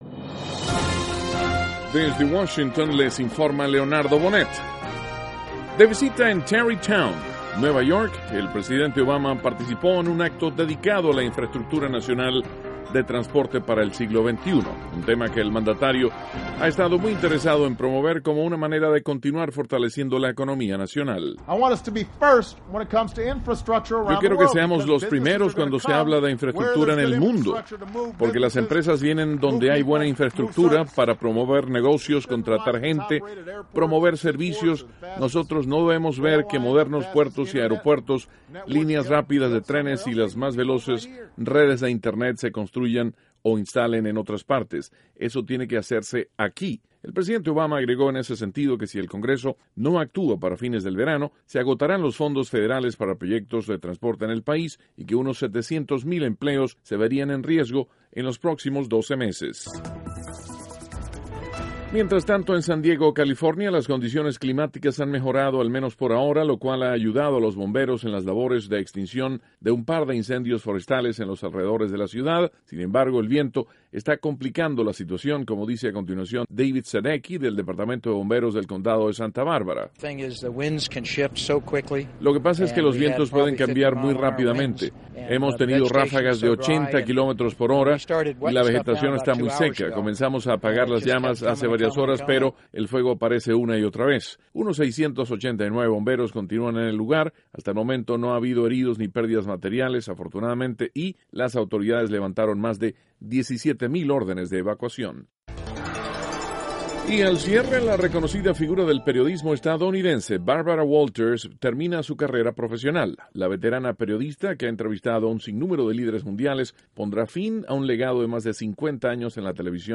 NOTICIAS - MIÉRCOLES, 14 DE MAYO, 2014
Duración: 3:08 Contenido: El presidente Obama promueve el fortalecimiento de la infraestructura nacional. (Sonido Obama) Mejoran condiciones climáticas en San Diego, California, donde bomberos combaten incendios forestales.
El vocero de la Casa Blanca, Jay Carney, habla de la jubilación de la legendaria periodista estadounidense, Barbara Walters. (Sonido Carney)